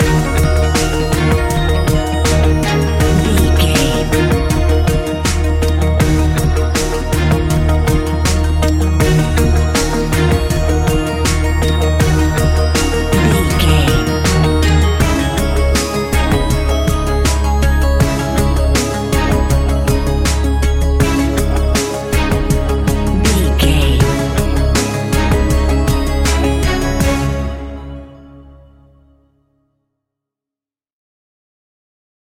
Ionian/Major
C♯
electronic
techno
trance
synths
synthwave
instrumentals